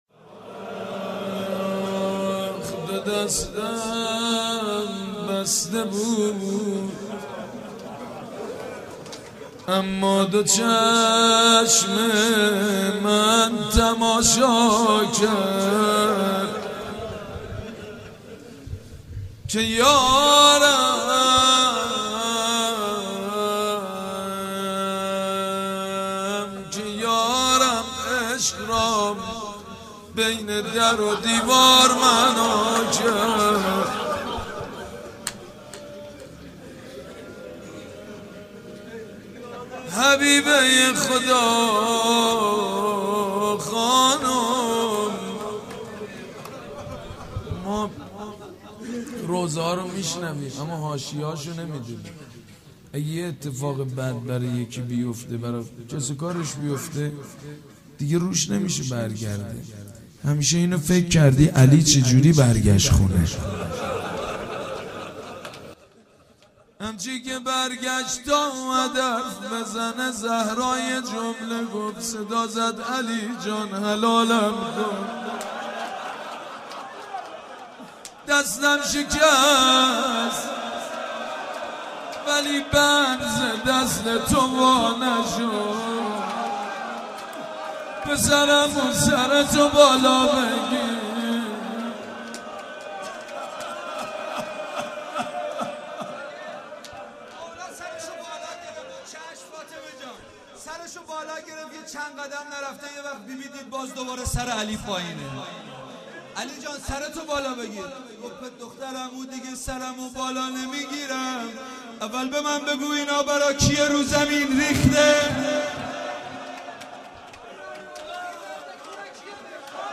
مداحی و نوحه
سینه زنی، شهادت حضرت فاطمه زهرا(س